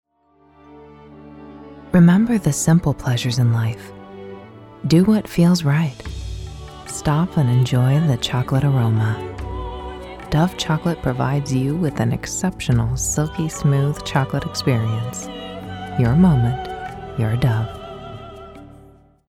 Female Voice Over, Dan Wachs Talent Agency.
Warm, Friendly, Conversational
Sensual